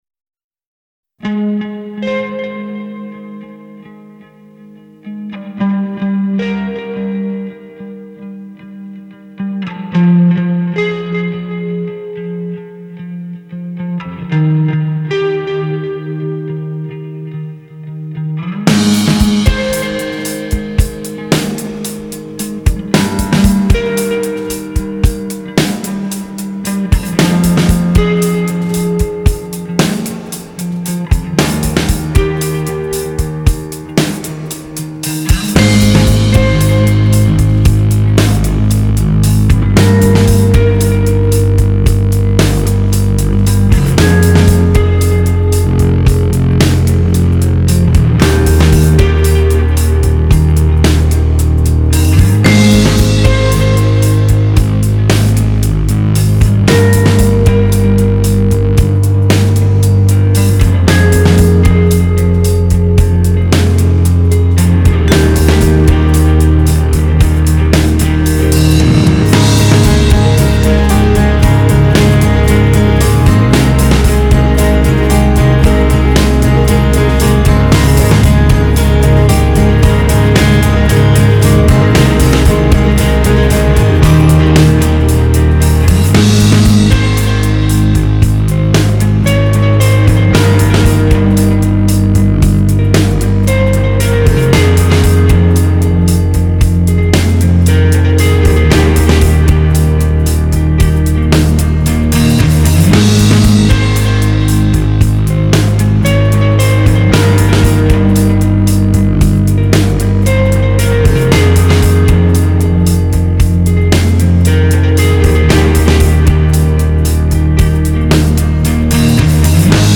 Alternative rock Indie rock Rock